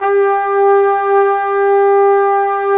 Index of /90_sSampleCDs/Roland L-CD702/VOL-2/BRS_Flugel Sect/BRS_Flugel Sect